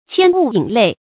牵物引类 qiān wù yǐn lèi
牵物引类发音
成语注音 ㄑㄧㄢ ㄨˋ ㄧㄣˇ ㄌㄟˋ